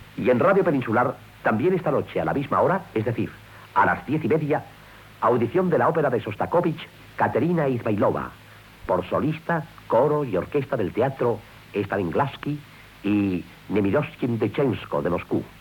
Avenç de programació. Contingut del programa "Teatro de la ópera" d'aqeulla nit, amb ,l'òpera "Katerina Izmailova", de Shostakovitch